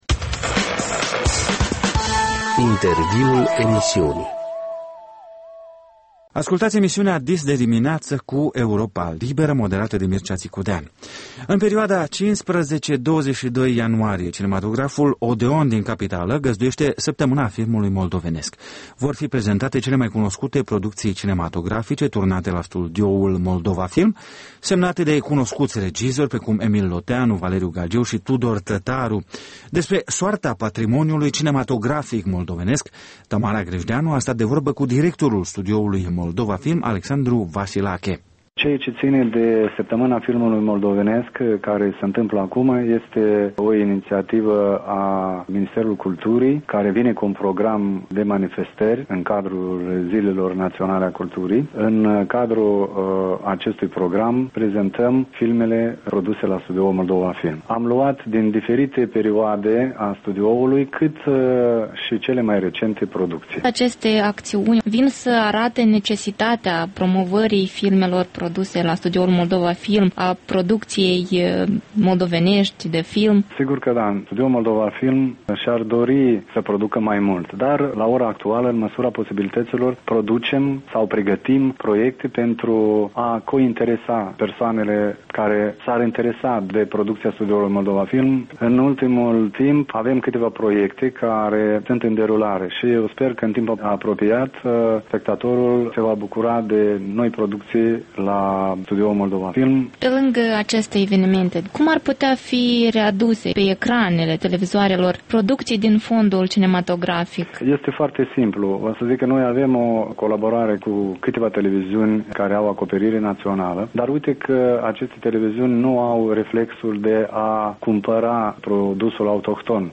Interviurile EL